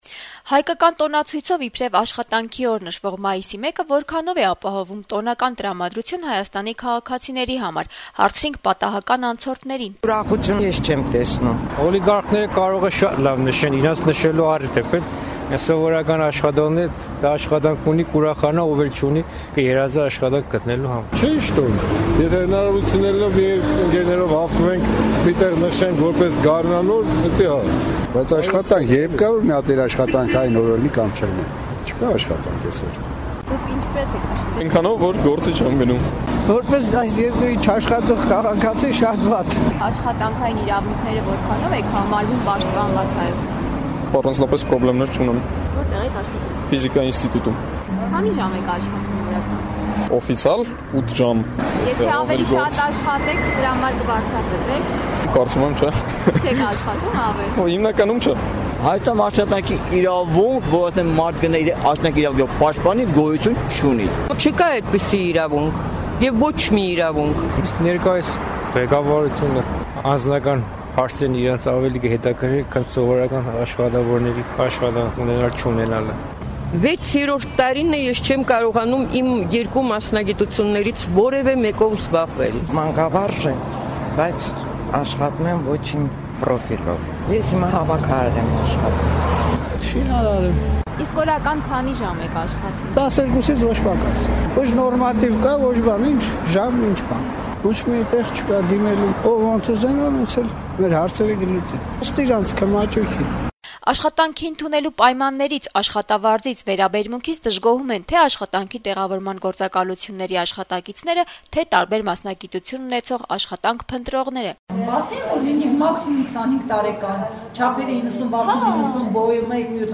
«Ազատություն» ռադիոկայանի հետ զրույցներում երեւանցիները «Աշխատանքի օրվա» կապակցությամբ իրենց կարծիքներն ու մտորումներն են հնչեցնում աշխատանքի տեղավորման, գործատուների հետ իրենց փոխհարաբերությունների եւ բազմաթիվ այլ հարցերի շուրջ: